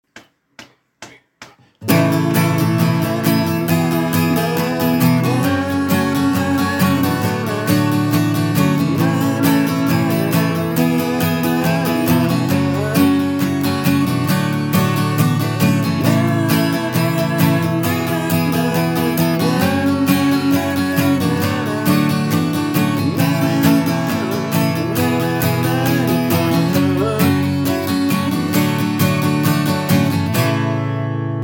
Ich machs mal mit Silben und lade den Refrain hier hoch; damit Du die Gesangs-Melodie hörst Refrain: G - D - Em - C G D La la la la la la la Em C La la la la la G D Em C La la la la La la La und dann Wiederholung Sorry, nur mit dem Handy aufgenommen, also Qualität nicht so gut, aber ich hoffe, Du kannst die Melodie hören.